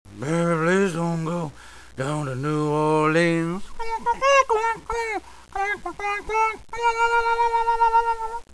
Tags: comedy tv comedians impressions impressonist impersonations